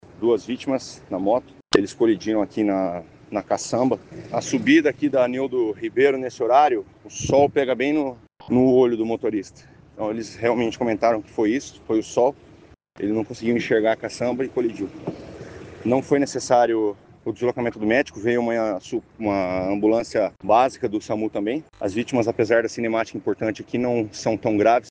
Apesar do impacto forte, eles não sofreram ferimentos graves, explicou o tenente.